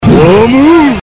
PLAY whammy sound effect
wham.mp3